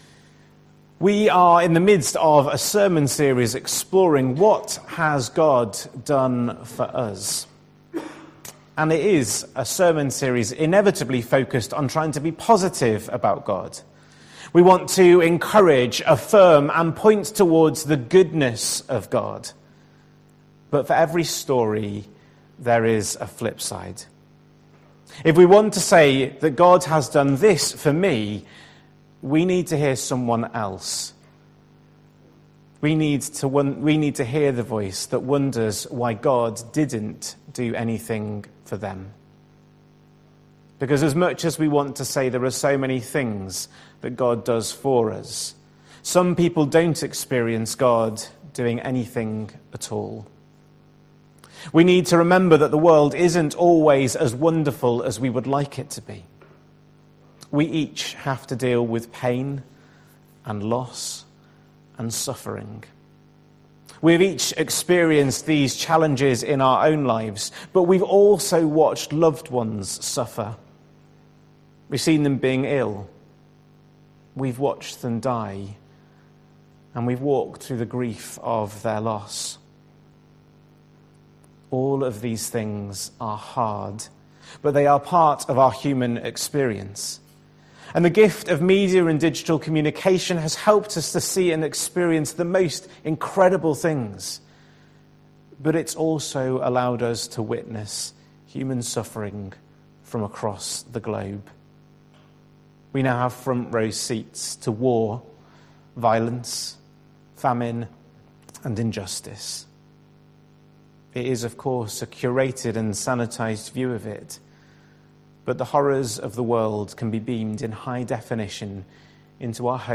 A talk based on Exodus 3.1-12.
We apologise that due to a technical problem bible reading was not recorded so is a recording of the talk only.